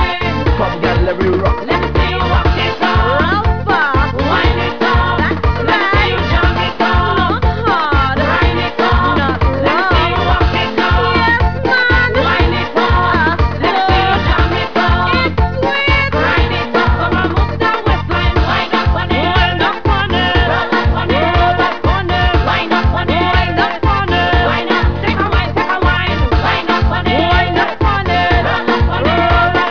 bouyon group